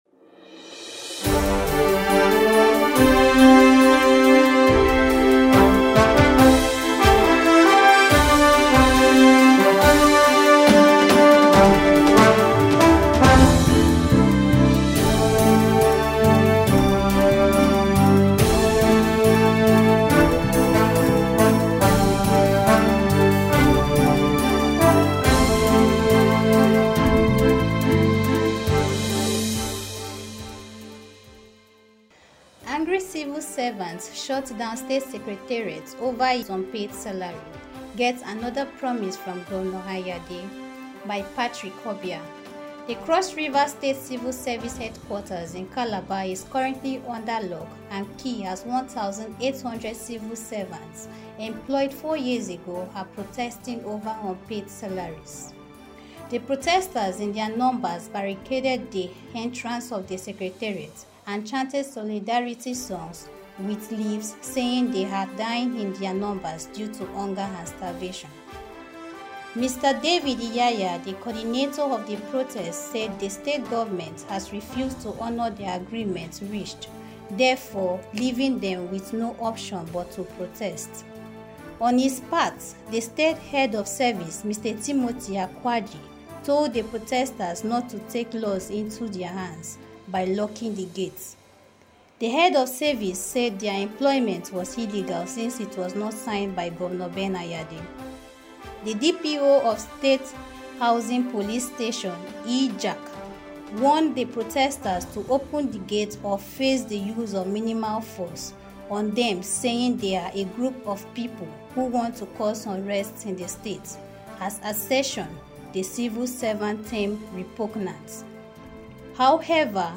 Breaking News Podcast Reports